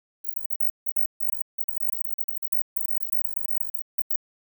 Shift it up by 16kHz and it’s inaudible to most people …
This is an inaudible watermark.png